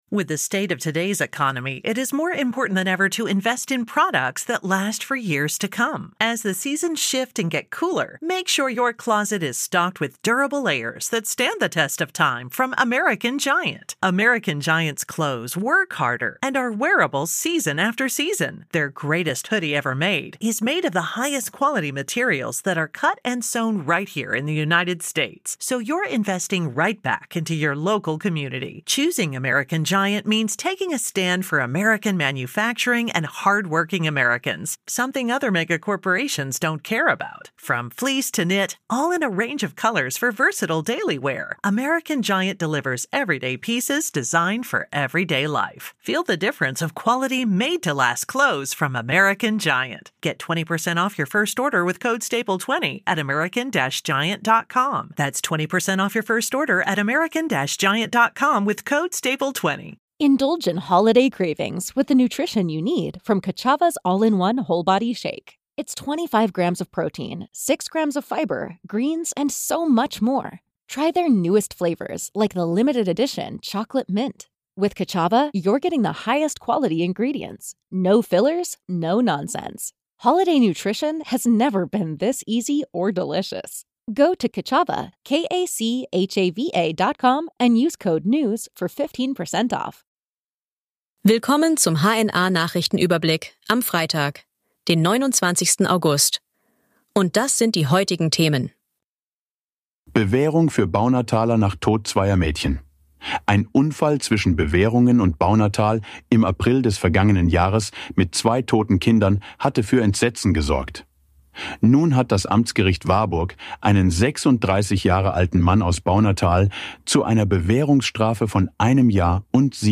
Nachrichten , Gesellschaft & Kultur